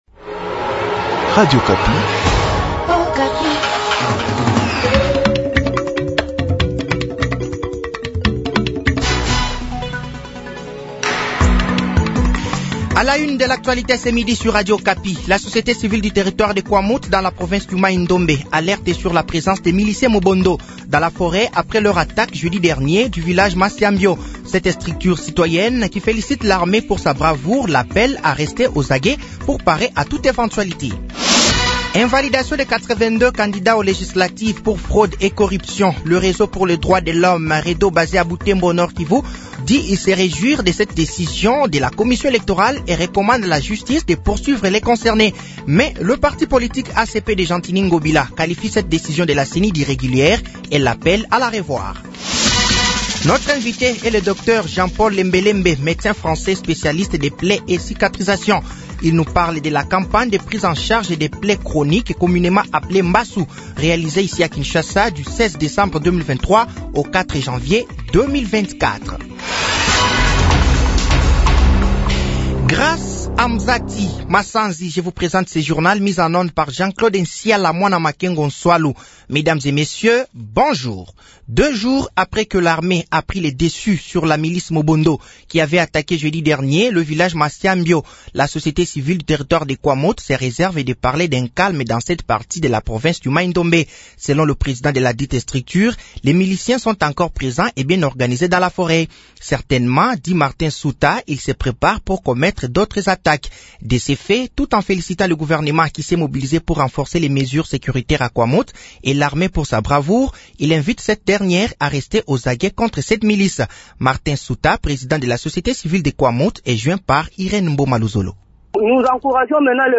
Journal français de 12h de ce dimanche 07 janvier 2023